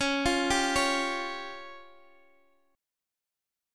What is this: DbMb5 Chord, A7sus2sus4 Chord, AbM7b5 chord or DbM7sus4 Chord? DbMb5 Chord